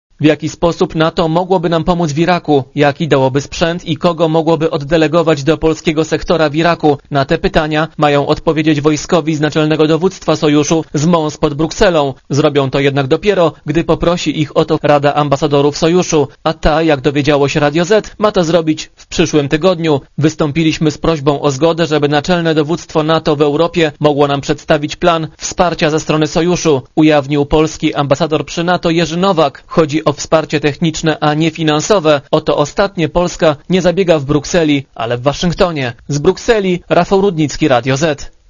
Korespondencja z Brukseli (150Kb)